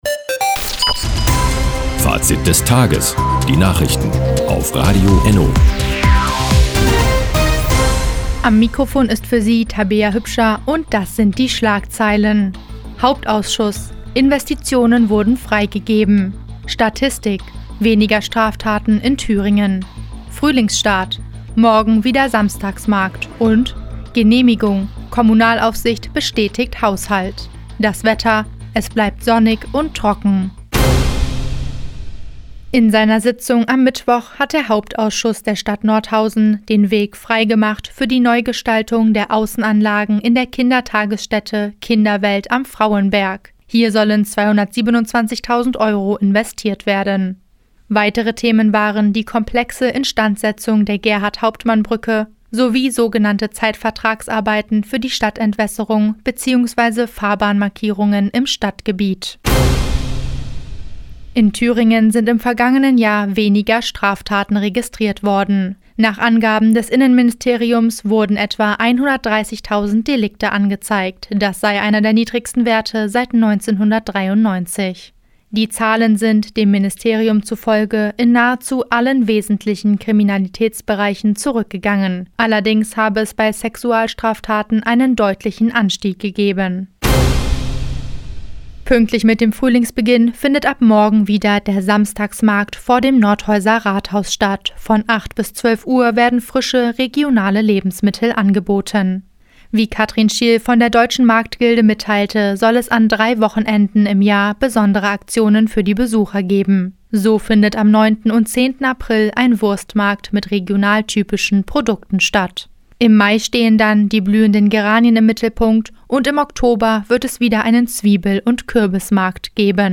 Die tägliche Nachrichtensendung ist jetzt hier zu hören...
Fr, 16:01 Uhr 25.03.2022 Neues von Radio ENNO Fazit des Tages Seit Jahren kooperieren die Nordthüringer Online-Zeitungen und das Nordhäuser Bürgerradio ENNO.